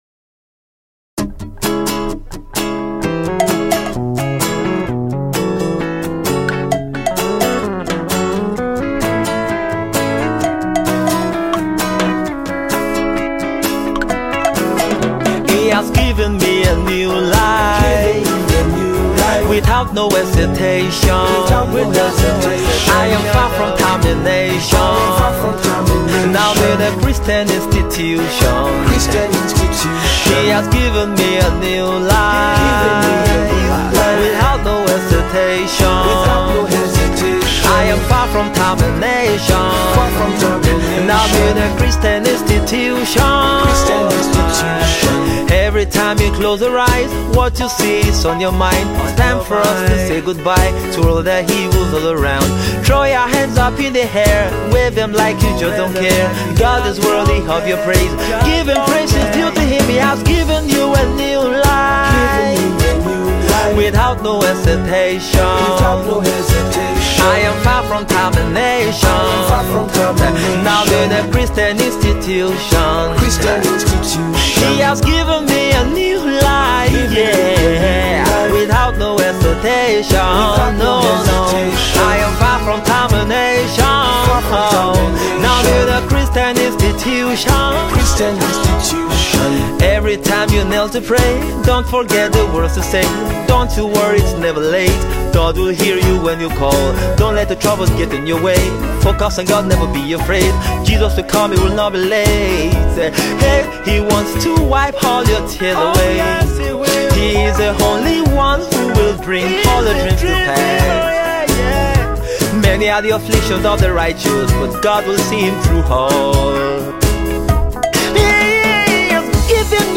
resulting in an usual blend of Afro Soul and Fuji.